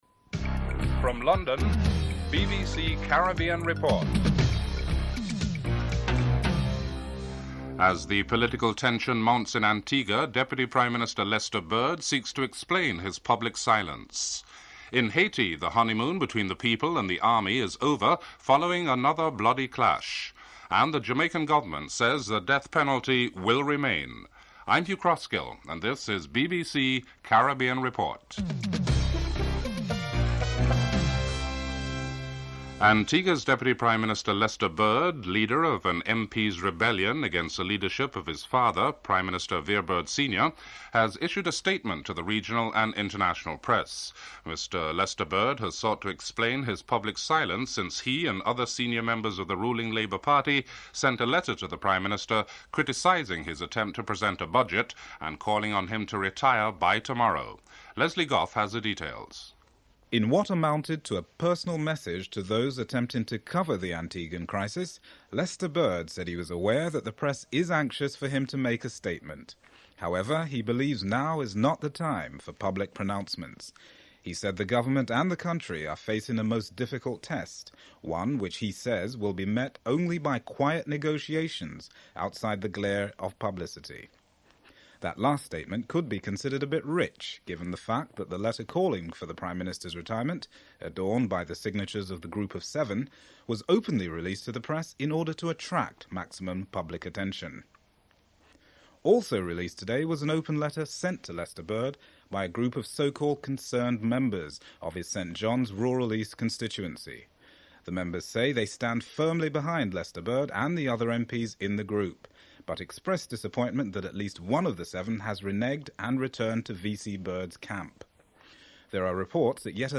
Segment 5, contains clips of speakers at the session.